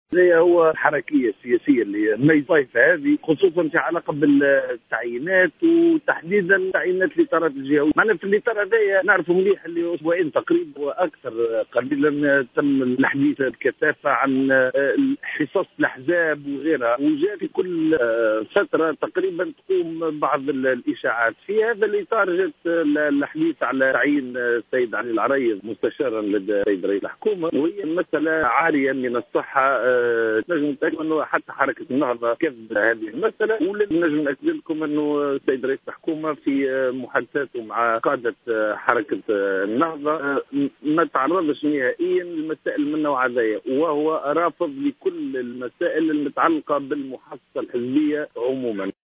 نفى مستشار الإعلام و الثقافة برئاسة الحكومة ظافر ناجى في تصريح لجوهرة "اف ام" ما يتم تداوله من أخبار حول تعيين القيادي بحركة النهضة علي العريض مستشارا برئاسة الحكومة،مؤكدا رفض رئيس الحكومة الحبيب الصيد لمسألة التعيينات وفقا للمحاصصة الحزبية على حد قوله.